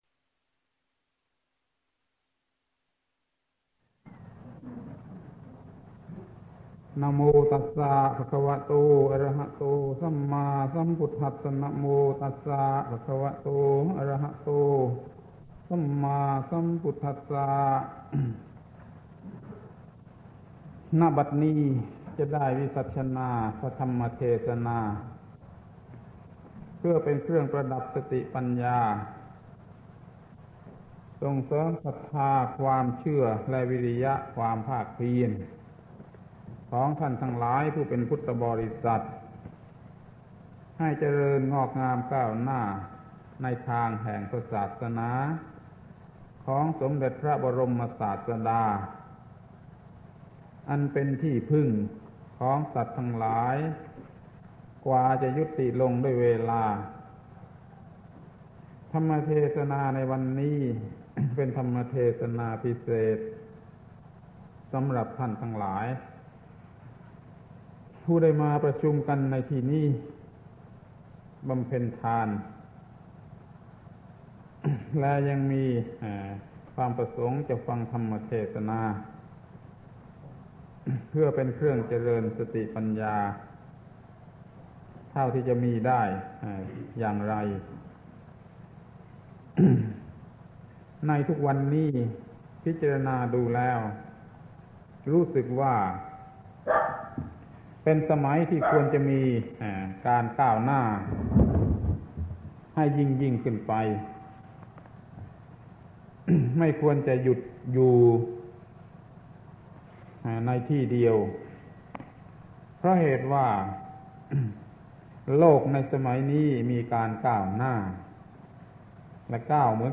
เทศน์แก่คณะแสวงบุญชาวเชียงรายที่มาทอดผ้าป่าประมาณ ๕๐ คน ที่โรงธรรม